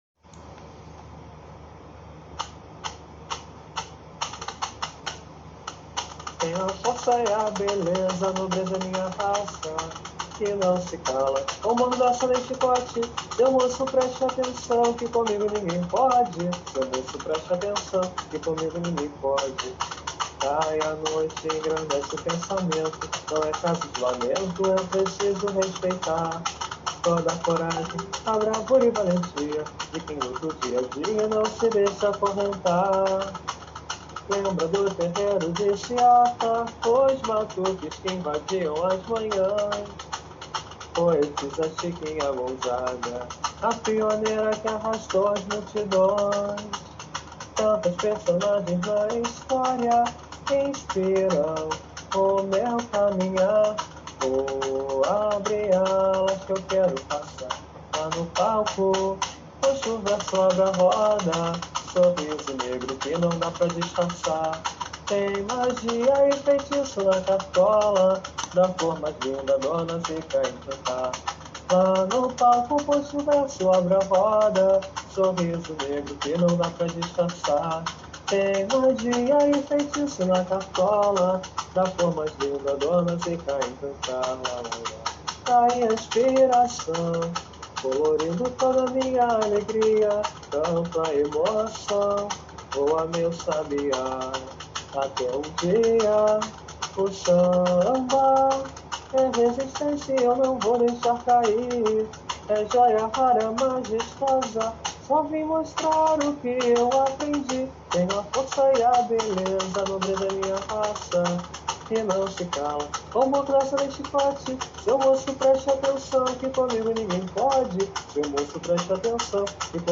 Samba  06